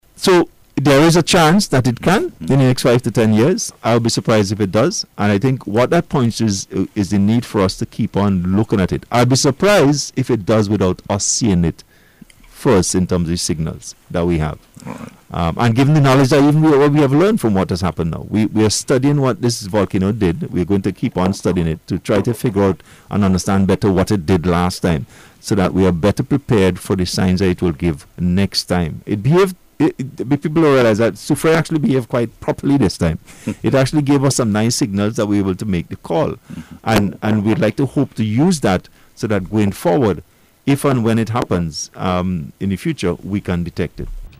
Monday’s Face to Face Program on NBC Radio was held to reflect on the first anniversary of the April 2021 volcanic eruption.